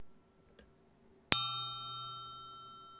マイクロフォンは、SONYのワンポイントステレオ録音用、ECM-MS907を使いました。
7 これは余韻が長いですね。
トライアングルを手に持って、軽く打ってみました。
triangle1.wav